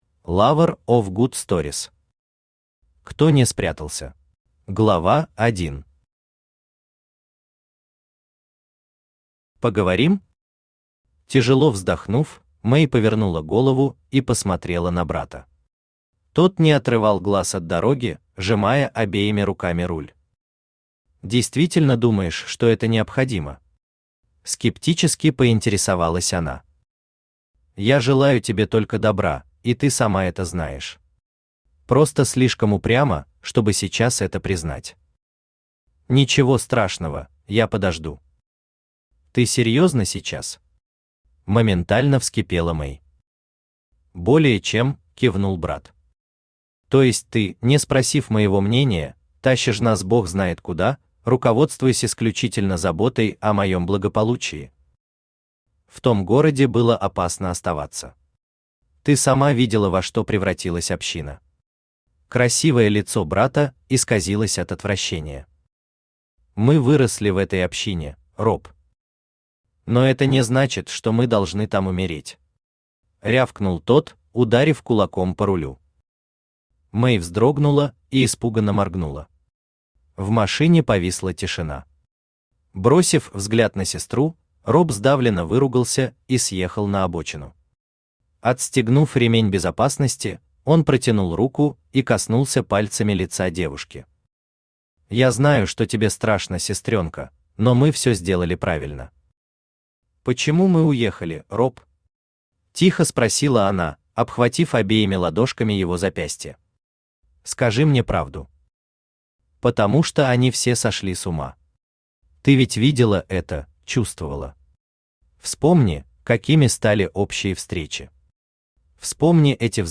Аудиокнига Кто не спрятался | Библиотека аудиокниг
Aудиокнига Кто не спрятался Автор Lover of good stories Читает аудиокнигу Авточтец ЛитРес.